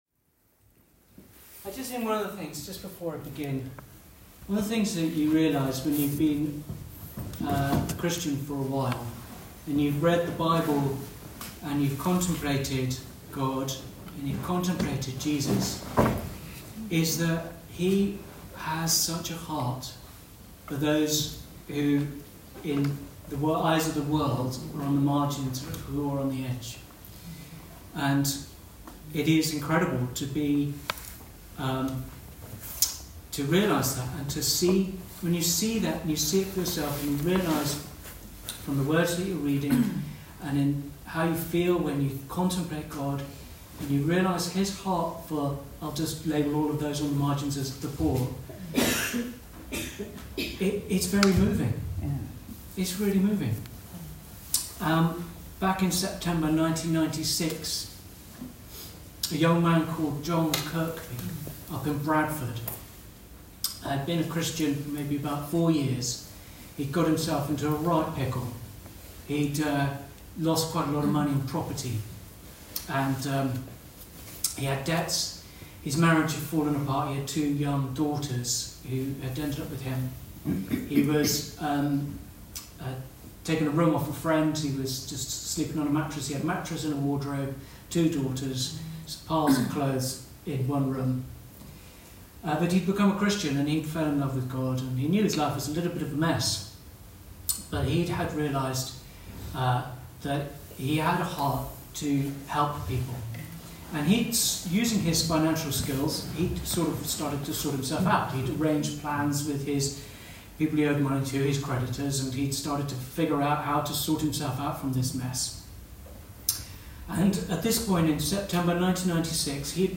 Weekly message from The King’s Church.